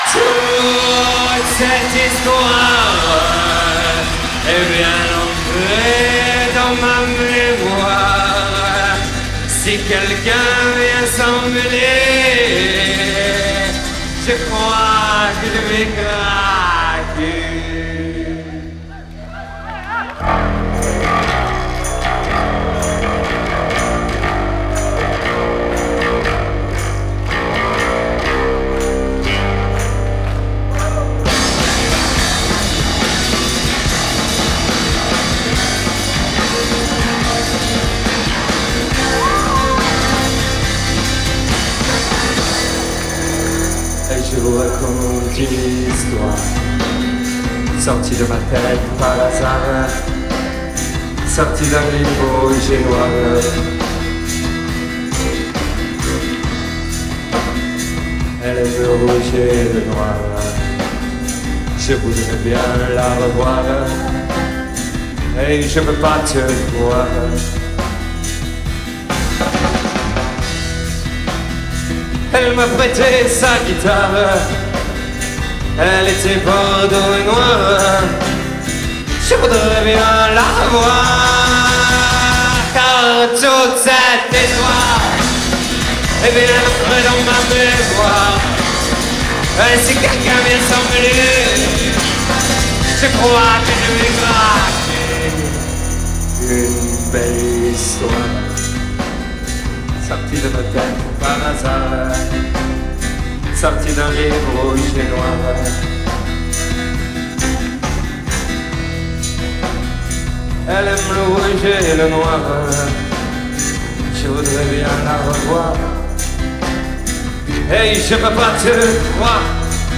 Live à La Route du Rock, Saint-Malo _ 1997